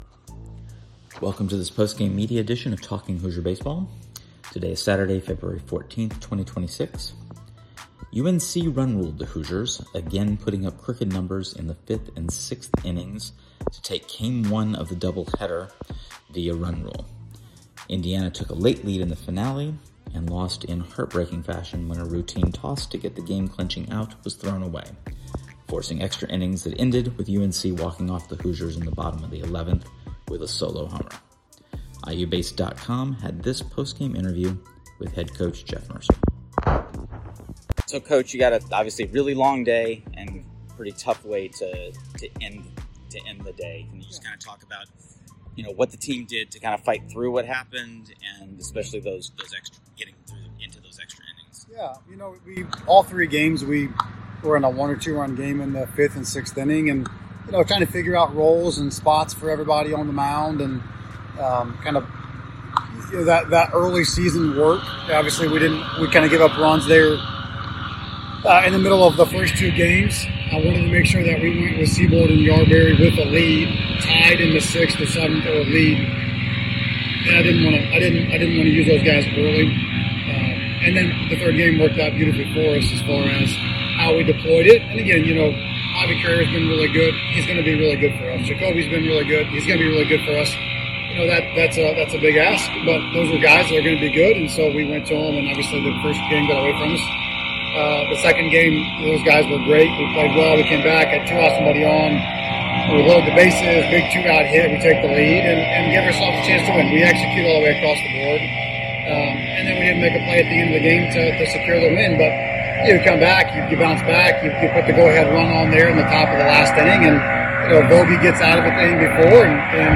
Postgame Media – Saturday doubleheader at North Carolina